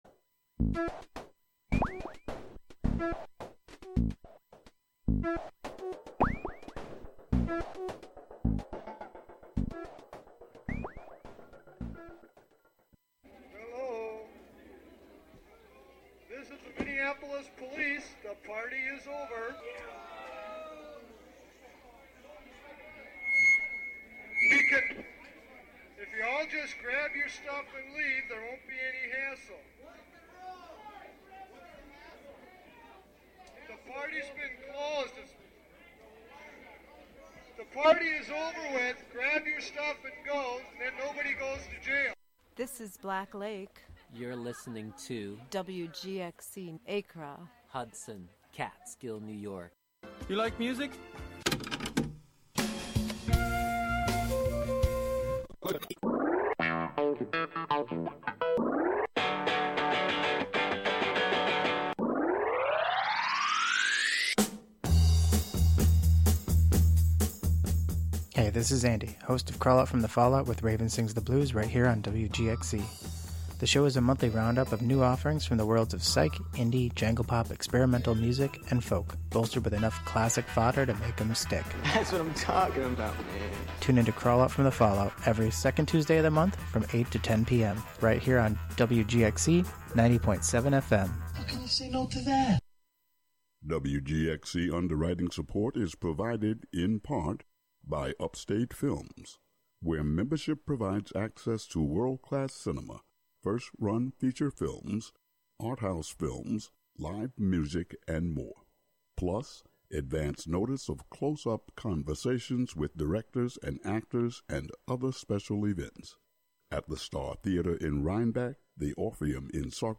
The show investigates the (human) voice as the individual expression of a connected system and asks if the sounds generated by other assemblages and systems could be considered as voice too. To extend this idea into practice, other non-human forms and systems will co-host the show, speak, and sing.